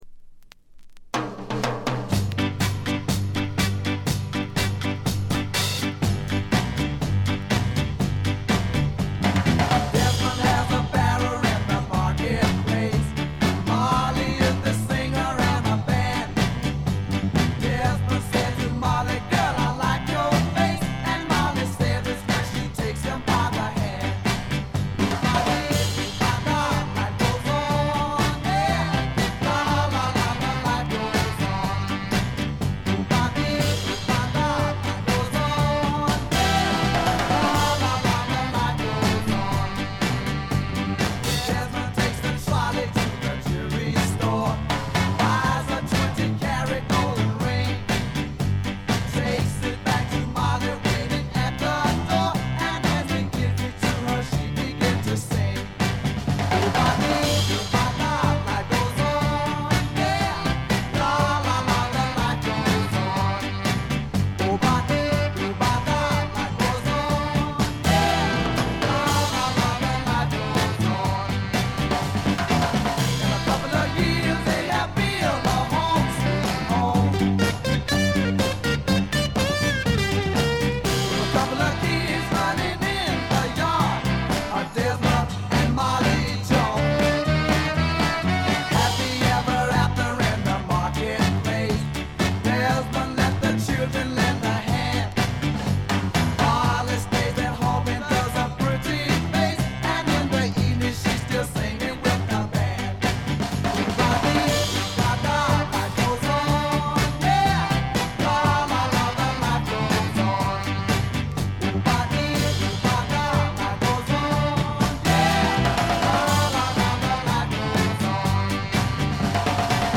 ところどころでチリプチ。
いうまでもなく栄光のサザンソウル伝説の一枚です。
試聴曲は現品からの取り込み音源です。